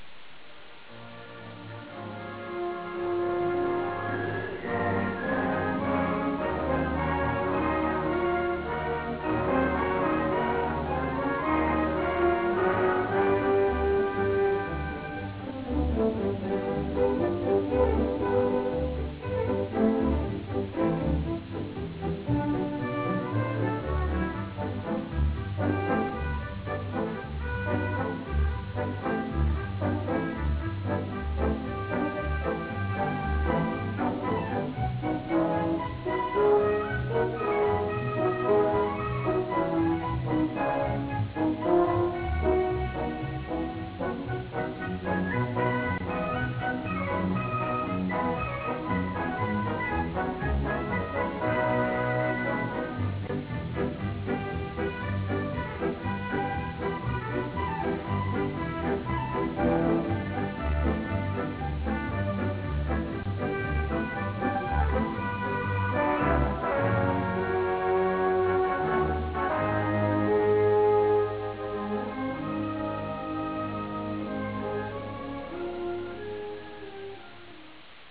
Musica:
Original Track Music